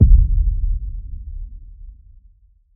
sphere_blowout.ogg